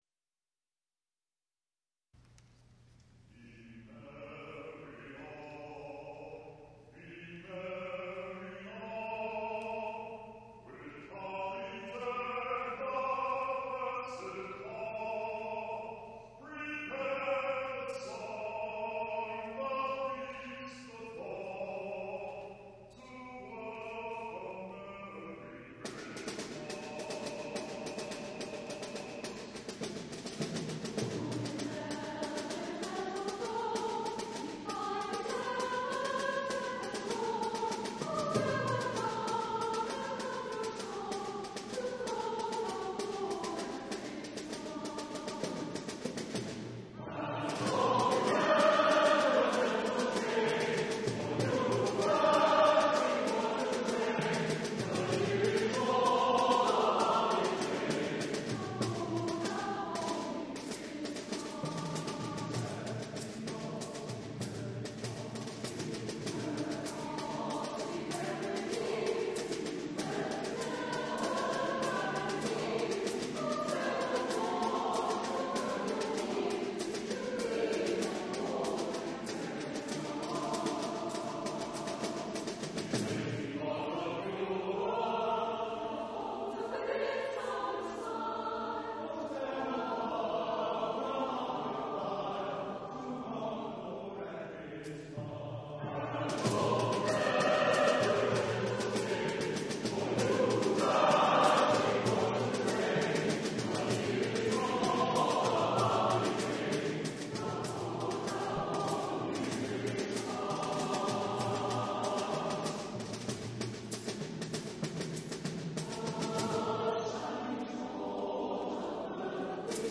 Lusty, rhythmic, full-throated.
SATB, Baritone solo, percussion.